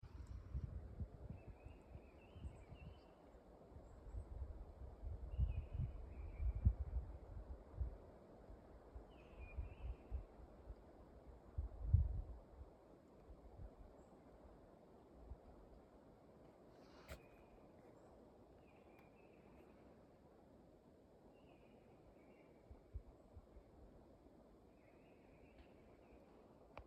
Birds -> Thrushes ->
Mistle Thrush, Turdus viscivorus
StatusSinging male in breeding season